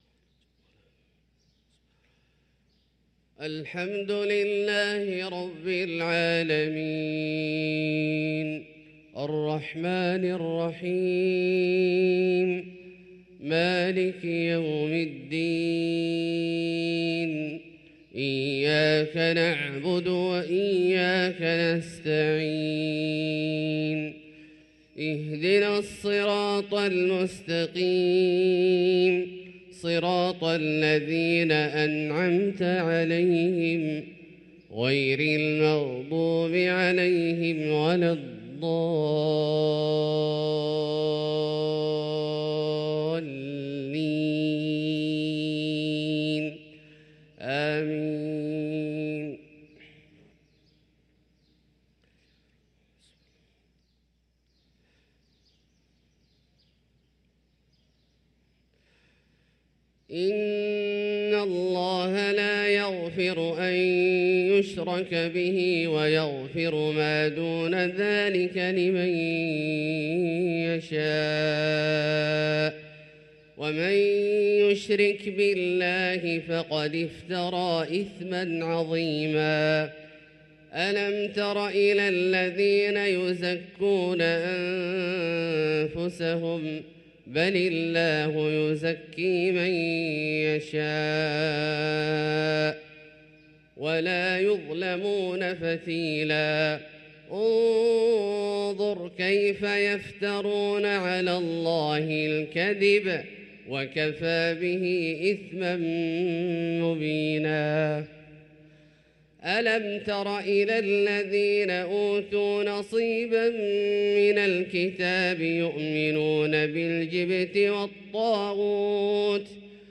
صلاة الفجر للقارئ عبدالله الجهني 16 ربيع الآخر 1445 هـ
تِلَاوَات الْحَرَمَيْن .